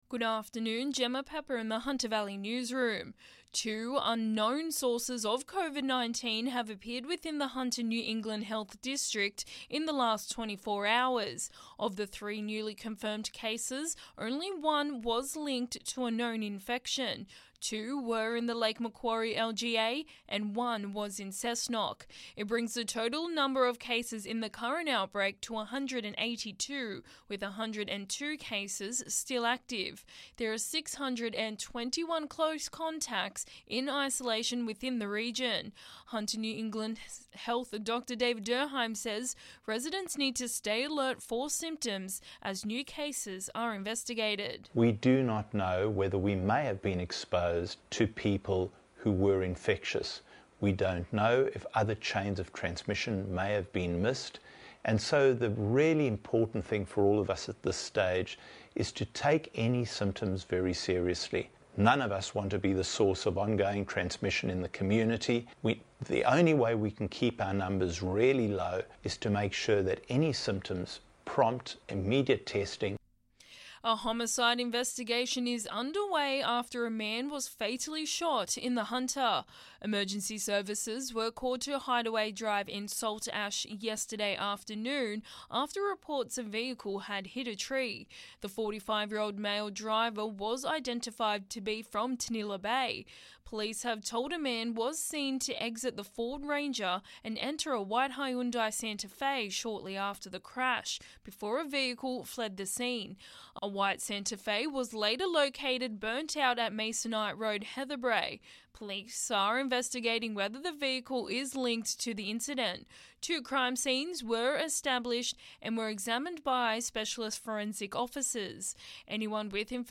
Listen: Hunter Local News Headlines 30/08/2021